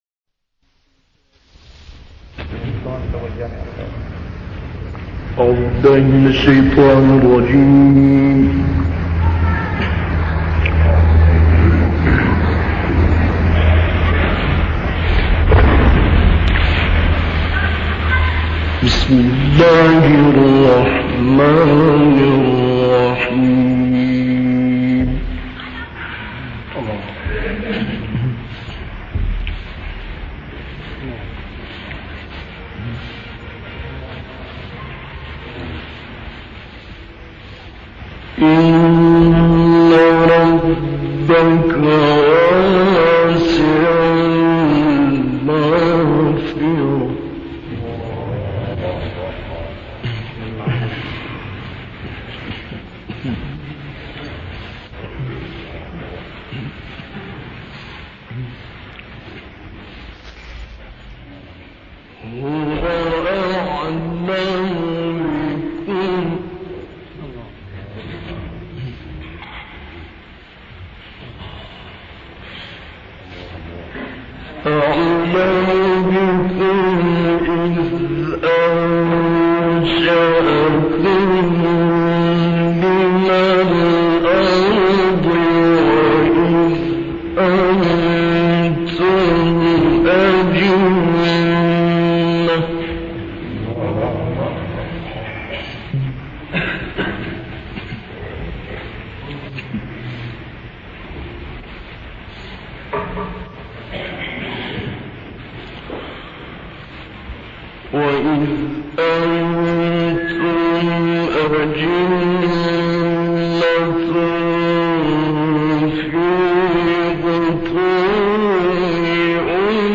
تلاوت آیاتی از سوره های نجم ، قمر و الرحمن که در سال 1959 در مسجد جامع اموی در دمشق انجام شد.